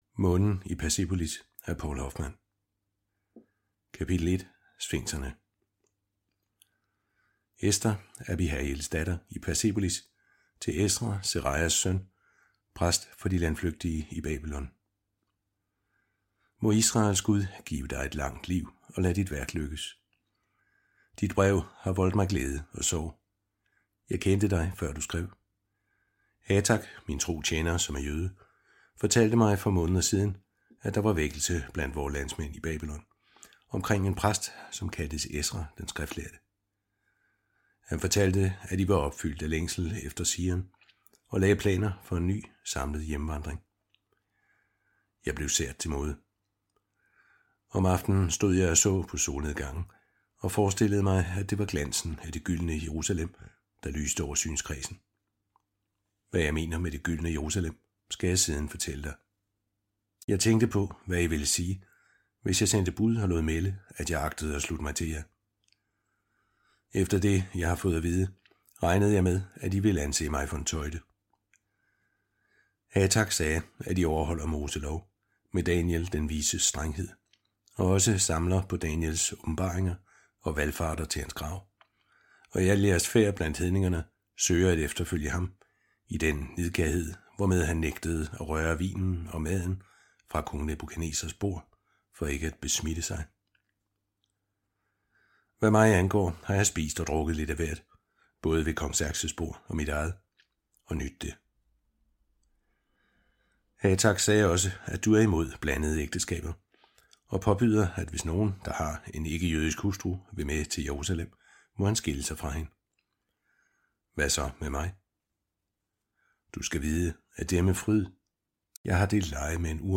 Hør et uddrag af Månen i Persepolis Månen i Persepolis Ester I Format MP3 Forfatter Poul Hoffmann Lydbog 149,95 kr.